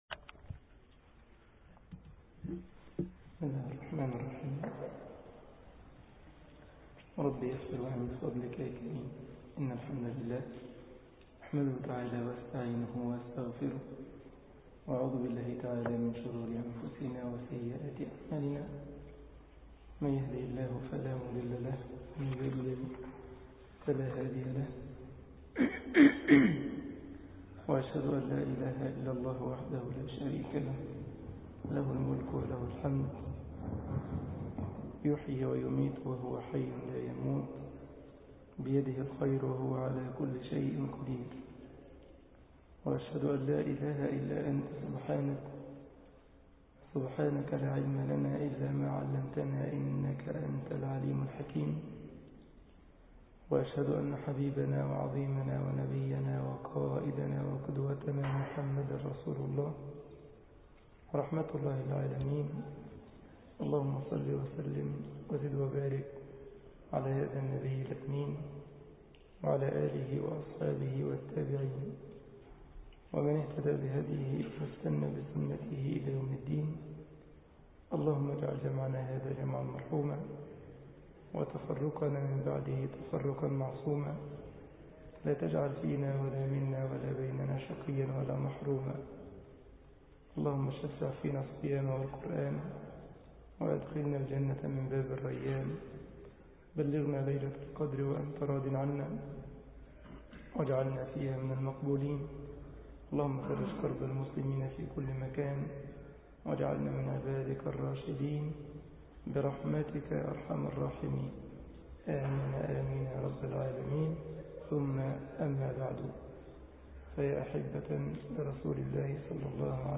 مسجد الجمعية الإسلامية بالسارلند ـ ألمانيا درس 11 رمضان 1433هـ